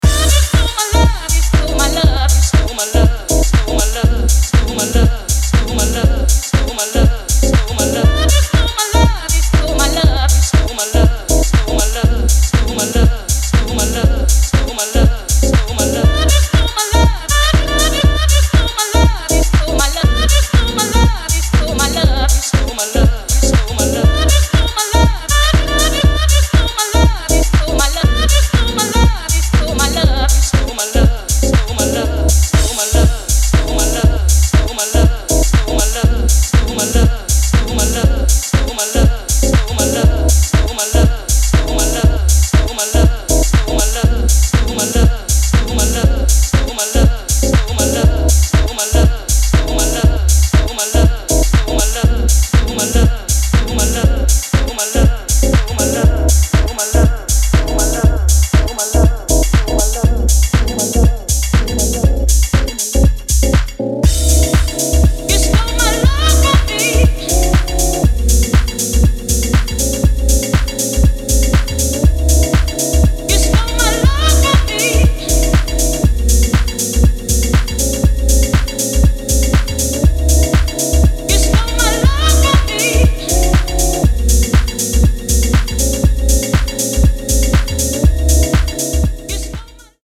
新旧のハウス・ファンに手放しで推せる、充実の内容です。